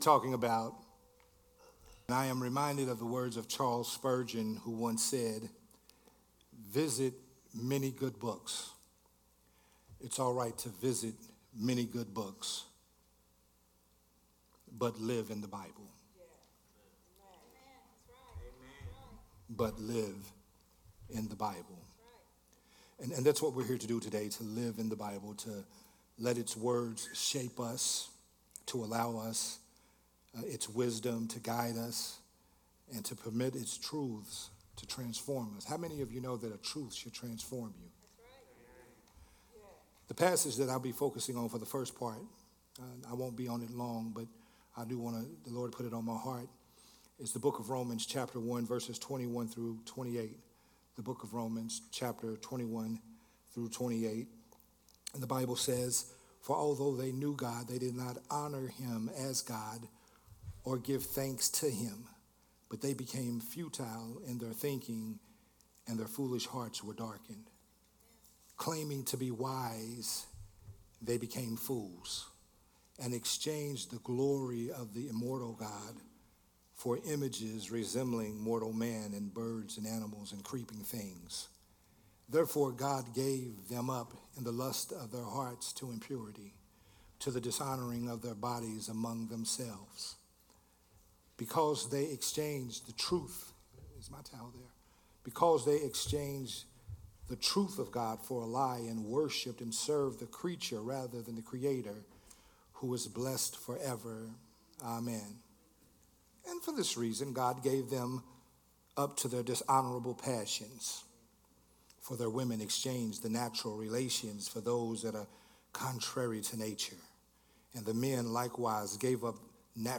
Sunday Morning Worship Service sermon